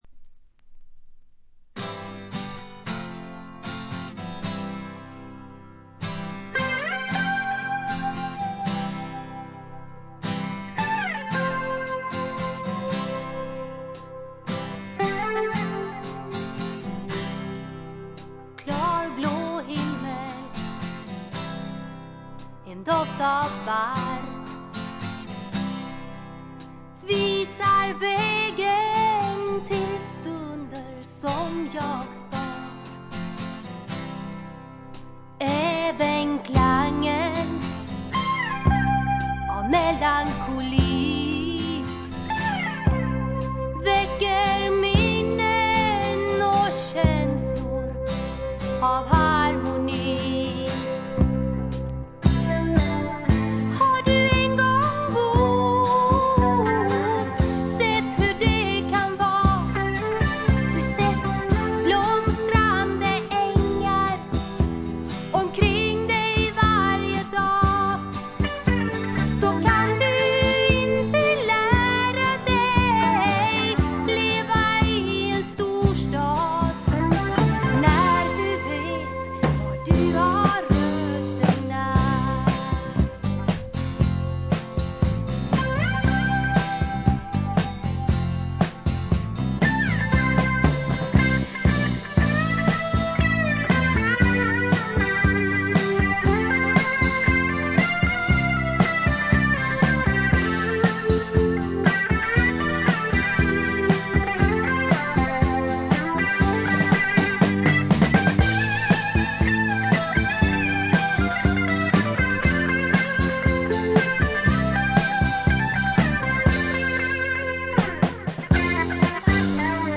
軽微なプツ音が2回ぐらい出たかな？という程度でほとんどノイズ感無し。
ちょっとポップなフォーク・ロックも素晴らしいし激渋のトラッドも良いです。
試聴曲は現品からの取り込み音源です。